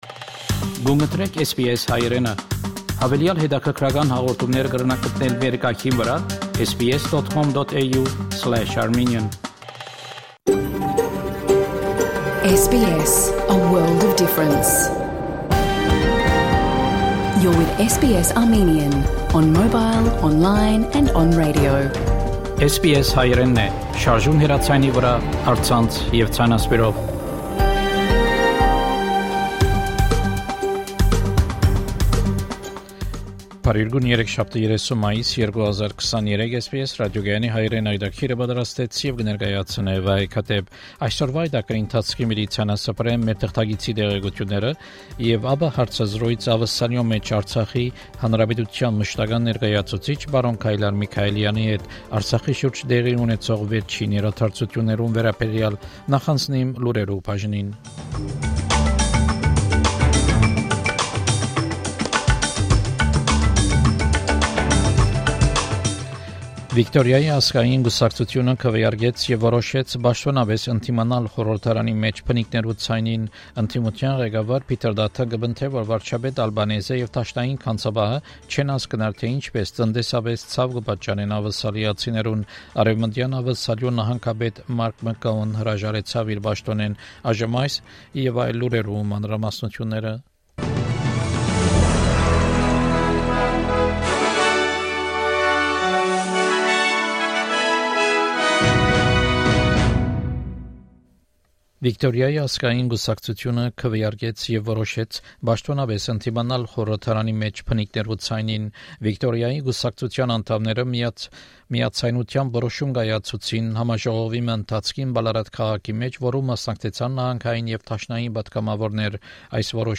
SBS Armenian news bulletin – 30 May 2023
SBS Armenian news bulletin from 30 May 2023 program.